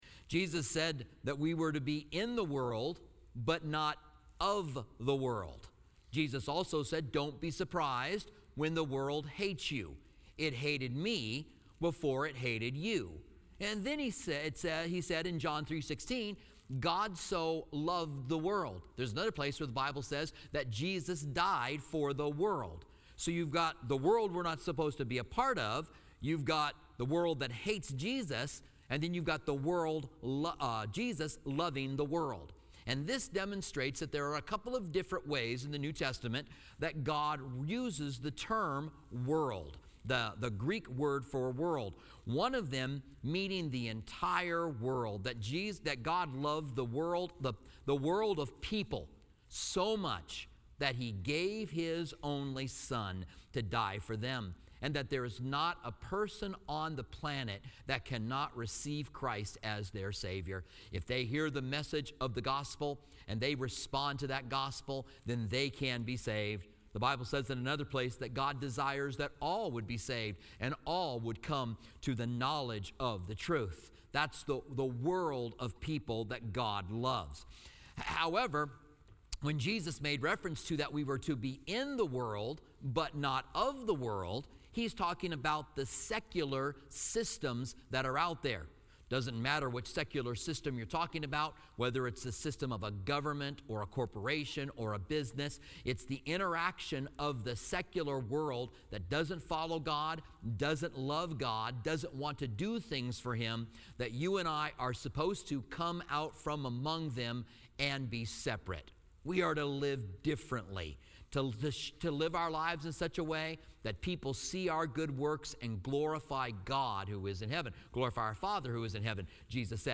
Commentary on 1 John